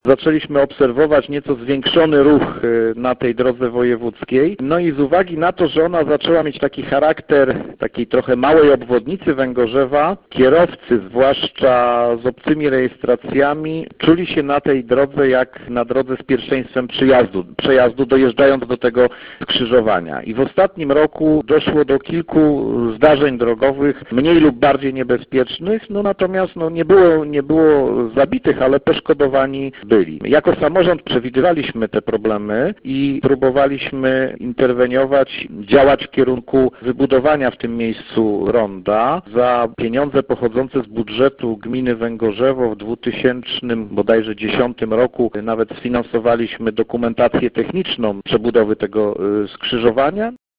Mówi Krzysztof Piwowarczyk burmistrz Węgorzewa